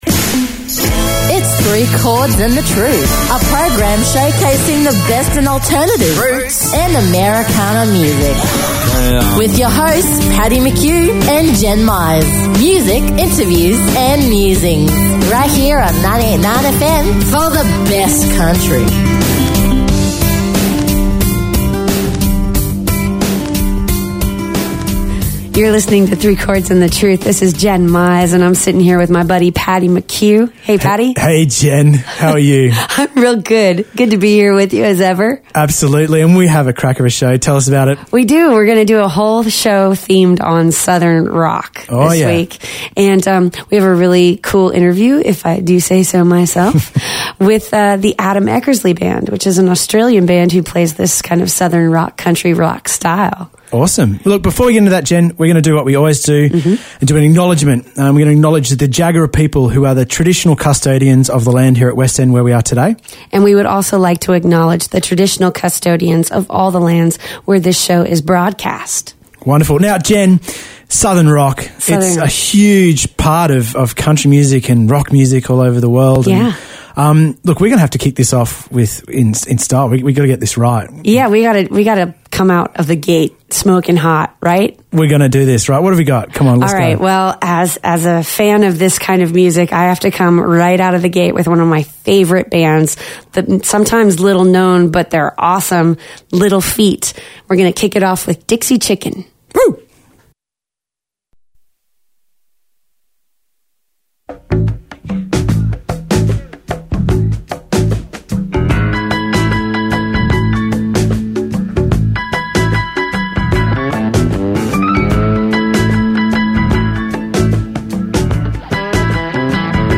Alt. Country, Roots & Americana
Music, interviews and musings every Thursday nights from 6pm and the repeat from 10am Sunday morning.